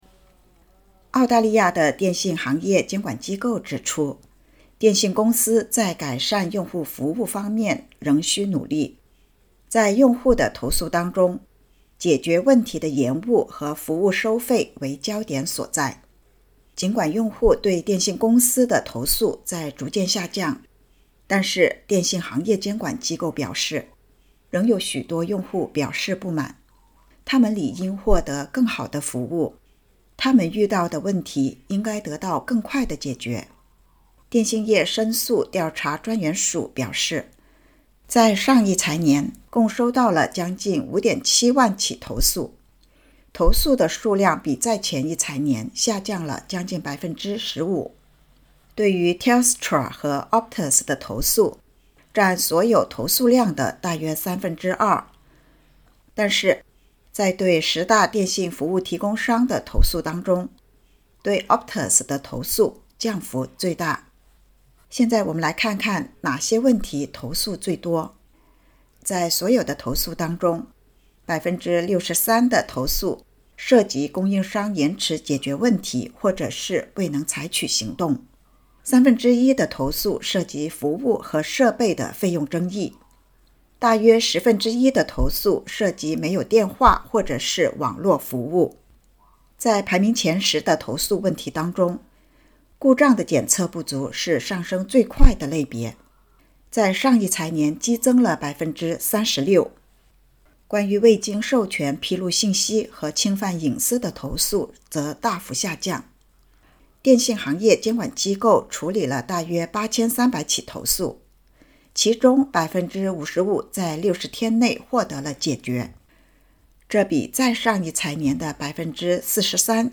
（请点击音频收听报道）